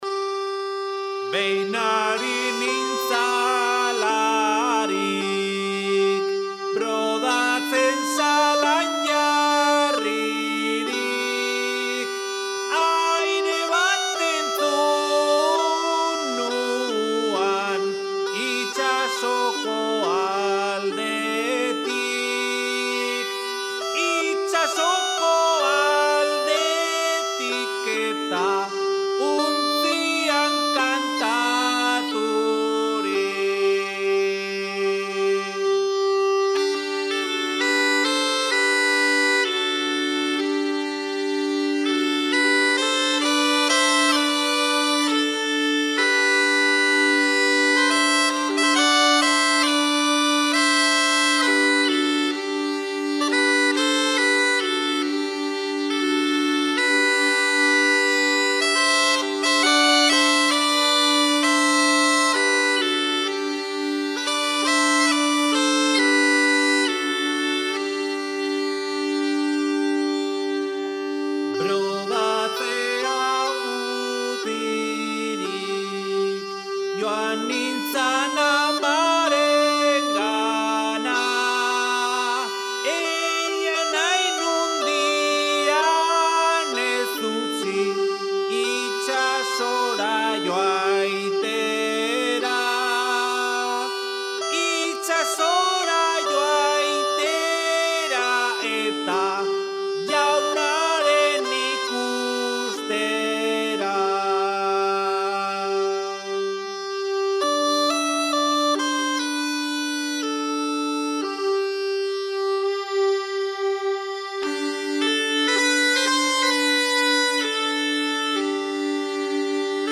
Oiartzungo Lezoti estudioan grabatuta.